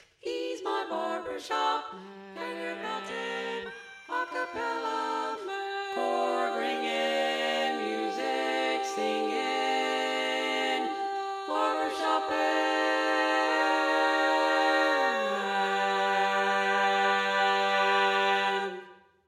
Key written in: G Major
Type: Female Barbershop (incl. SAI, HI, etc)